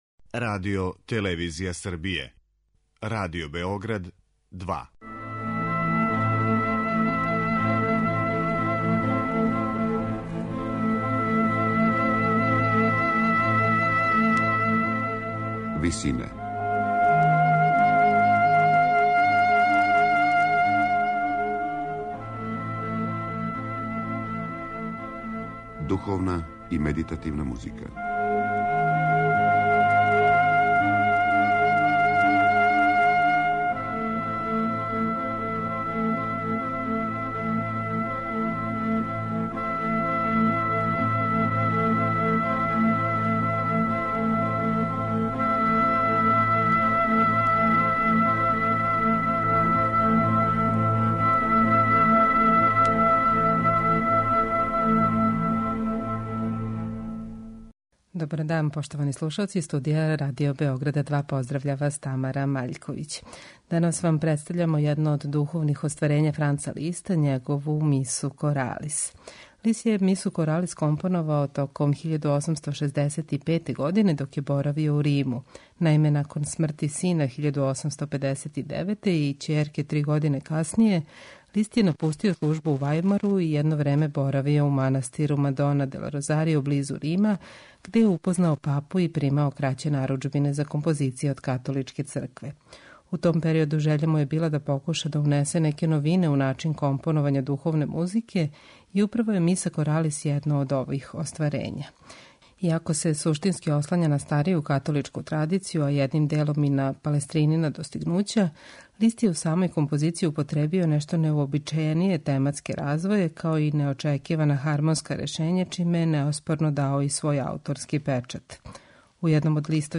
Дело је писано за мешовити хор и оргуље, а засновано је на грегоријанској традицији, као и на традицији касне ренесансе, нарочито на делима Палестрине.